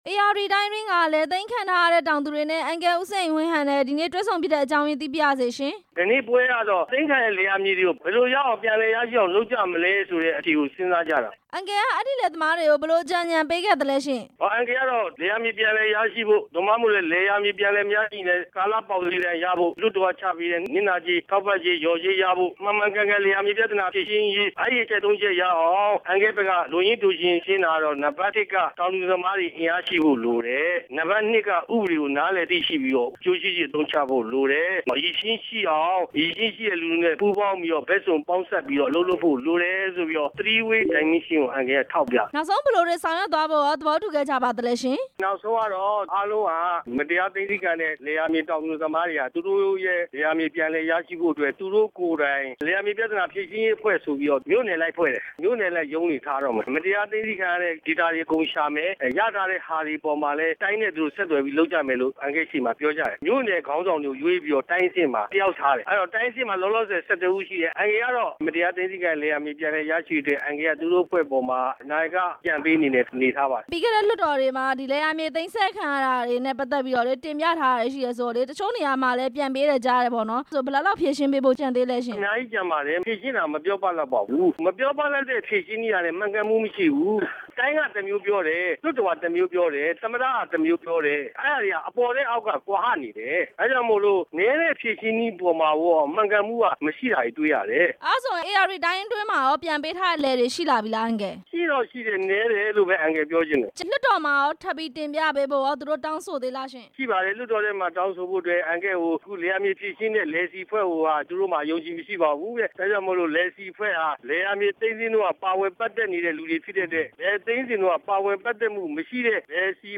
ဦးစိန်ဝင်းဟန် နဲ့ မေးမြန်းချက်